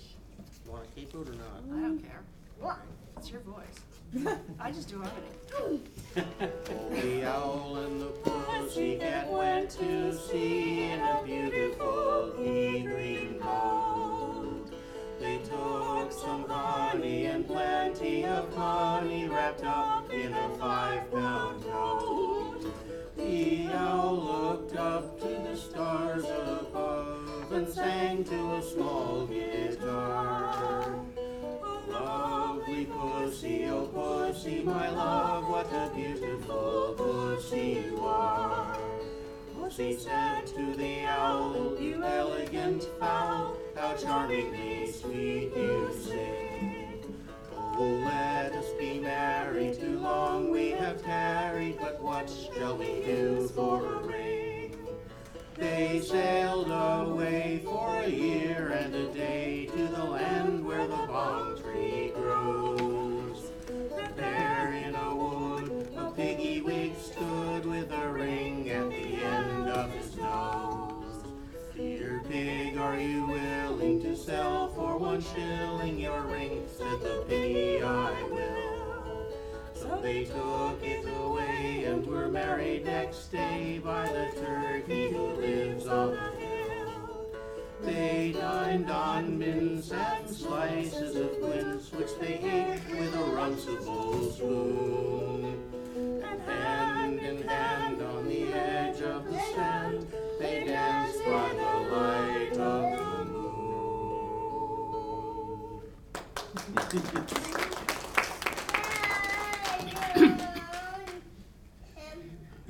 ... and our songs at the KinderFilk concert: